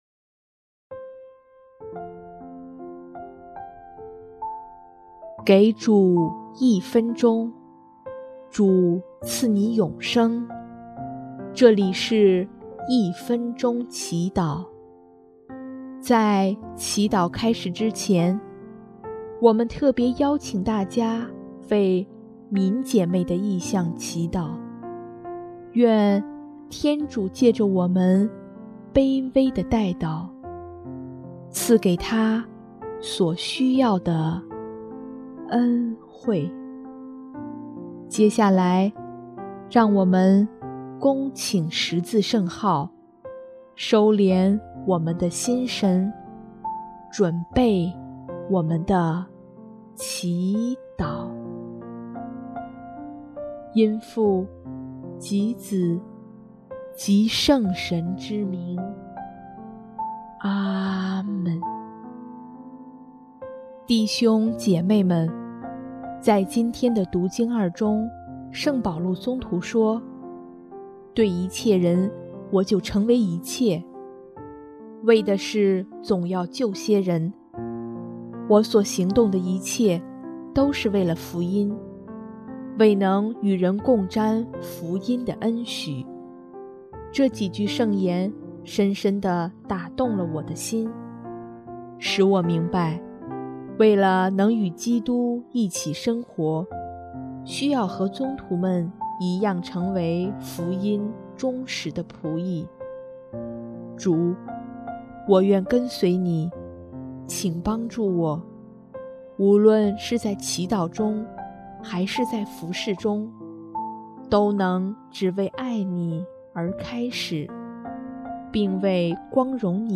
【一分钟祈祷】|2月4日 为爱基督而改变自己！